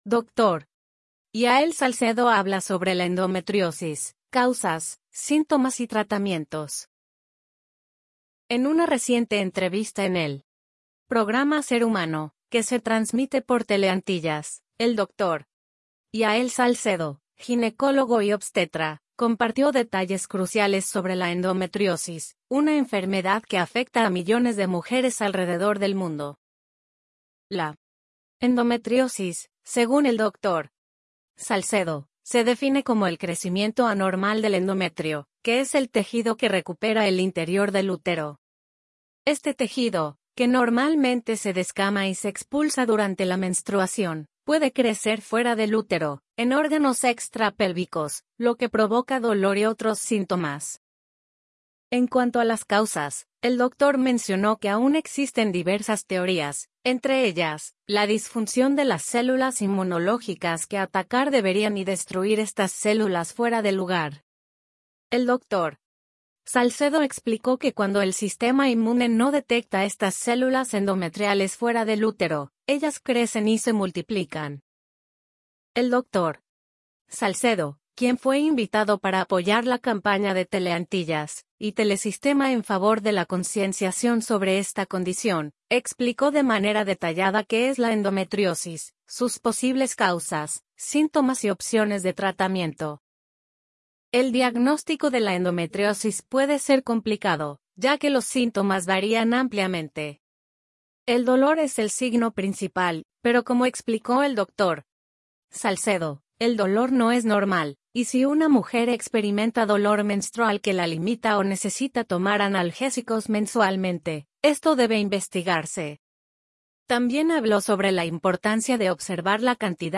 En una reciente entrevista en el programa Ser Humano